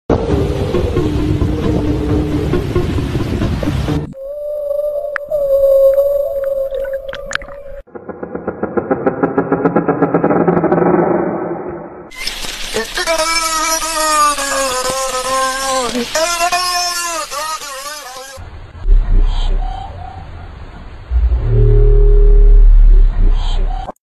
unexplained sounds sound effects free download